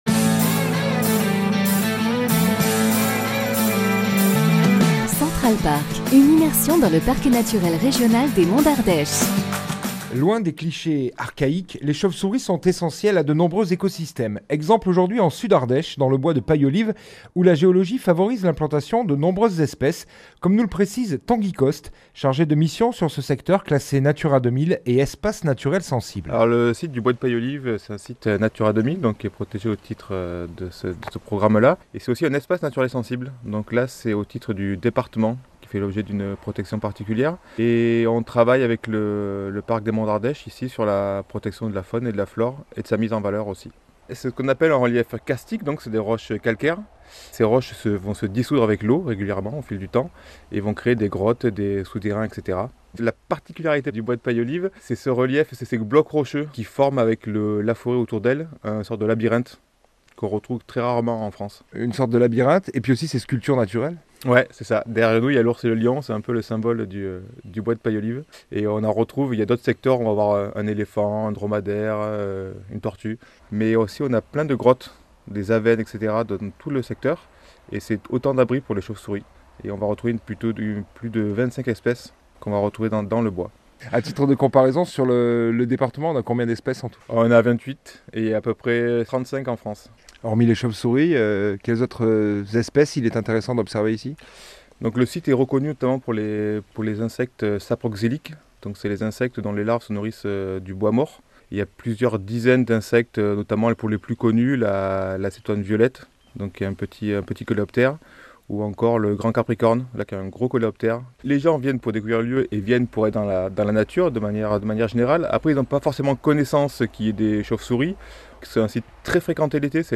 Pour cela il faut s'équiper d'un casque, pénétrer les cavités en silence et avec un éclairage limité.